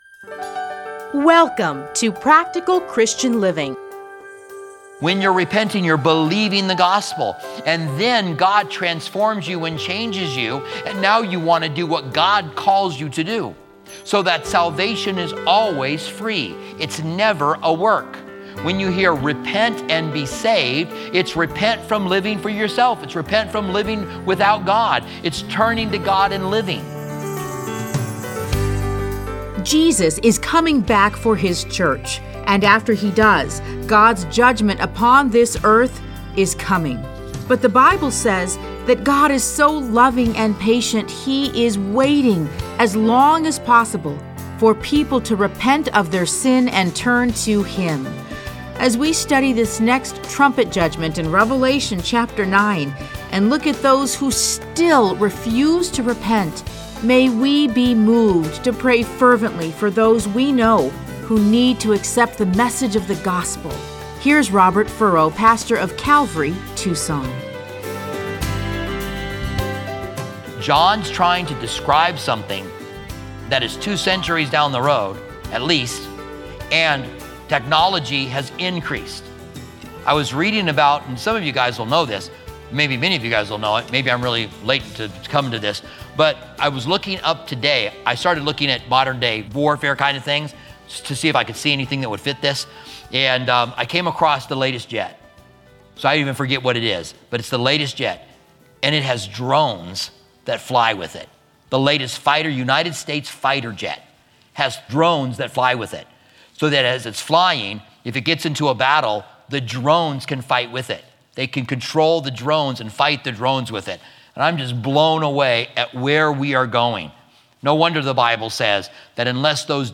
Listen to a teaching from Revelation 9:13-21.